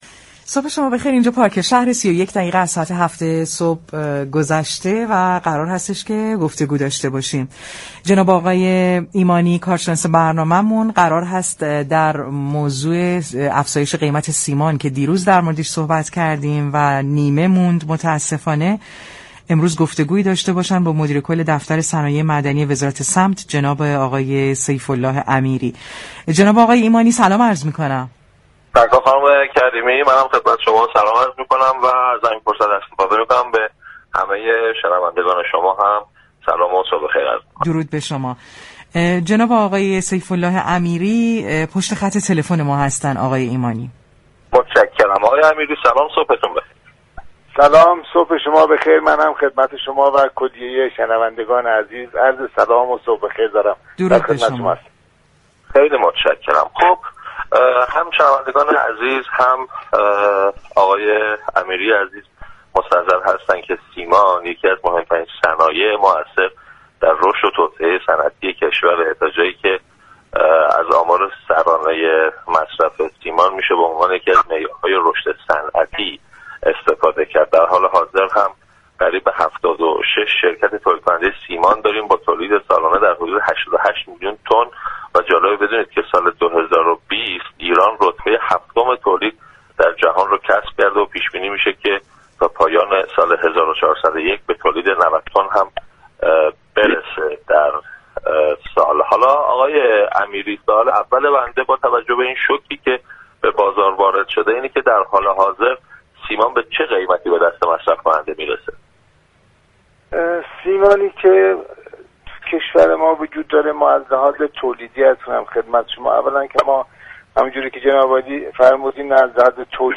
به گزارش پایگاه اطلاع رسانی رادیو تهران، سیف الله امیری مدیركل دفتر صنایع معدنی وزارت صمت، در گفتگو با برنامه پارك شهر رادیو تهران درخصوص گرانی سیمان در بازار گفت: ما در تولید سیمان مشكل نداریم و علاوه بر تولید، صادرات سیمان هم داشتیم در سه ماهه ی ابتدای سال 17 میلیون تن تولید داشتیم كه نسبت به سال گذشته بود، رشد حدود 4.8 درصدی داشتیم.